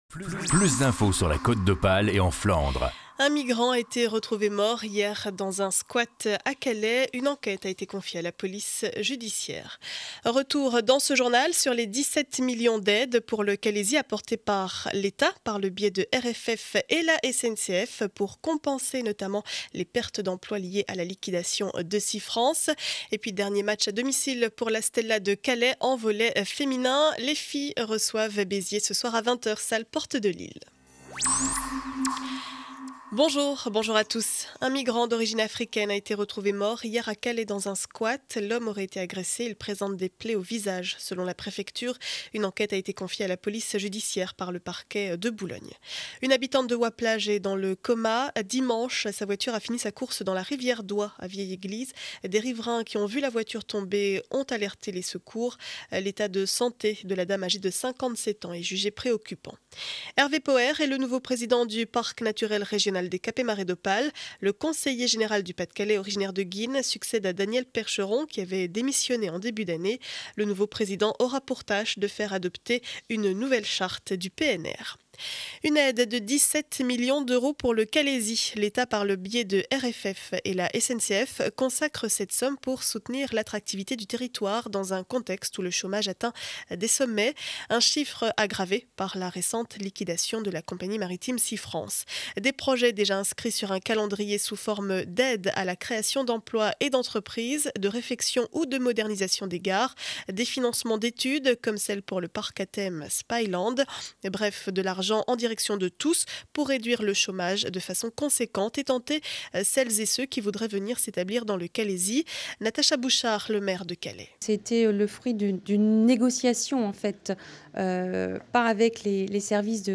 Journal du mardi 10 avril 2012 7 heures 30 édition du Calaisis.